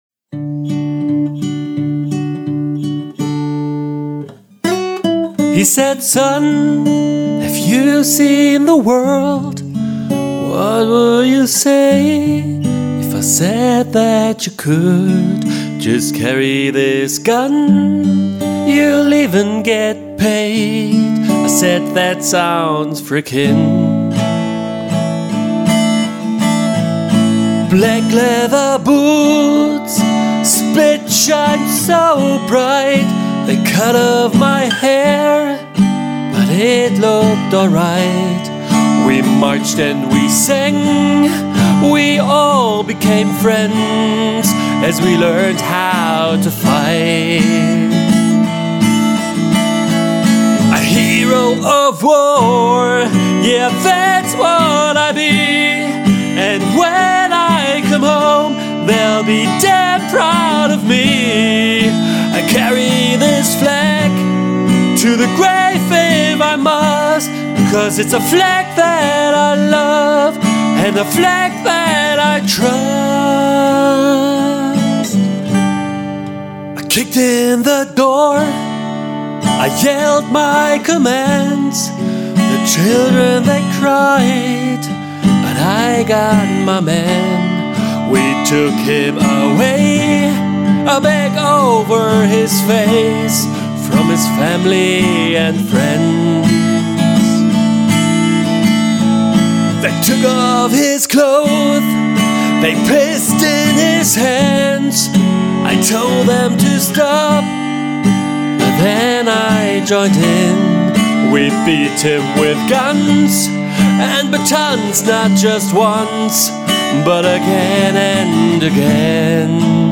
guitar & voice
Stimme & Gitarre – pur, emotional, authentisch.